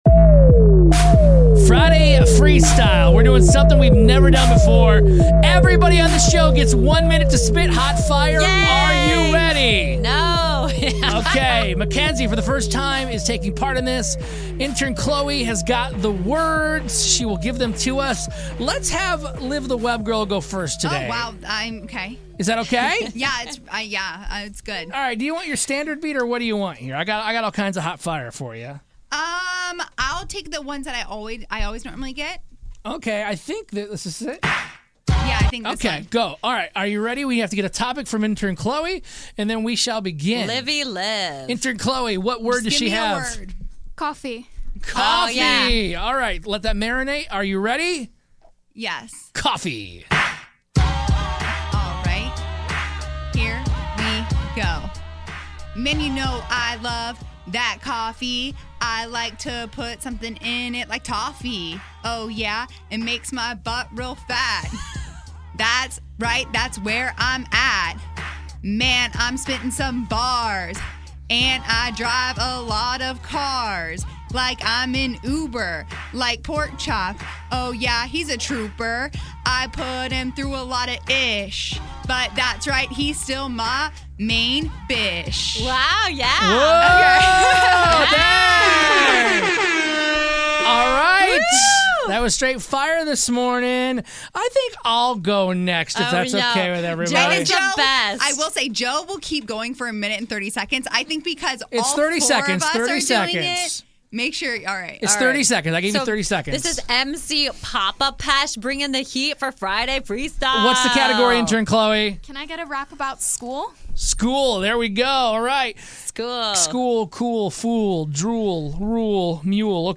Friday Freestyle Rap Battle
The whole show goes head to head for this weeks friday freestyle rap battle!